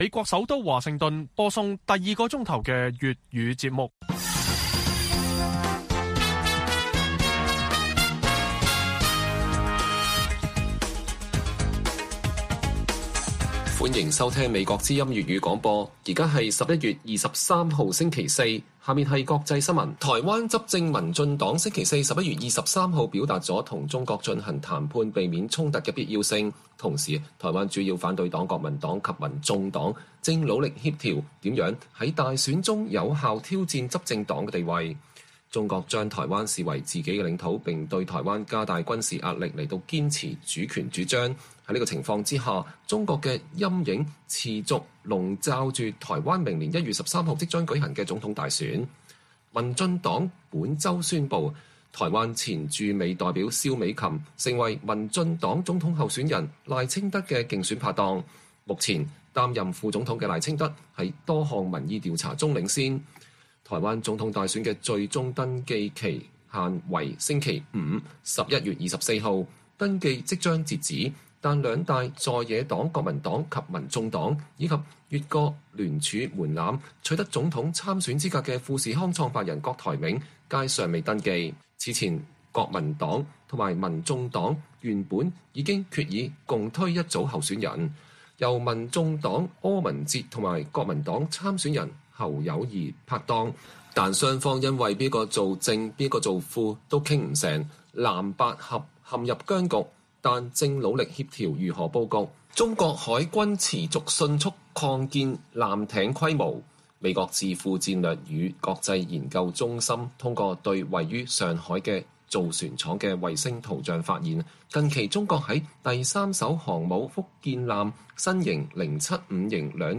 粵語新聞 晚上10-11點: 台灣在野黨進行三方會談力拼整合， 民進黨就表明與中共對話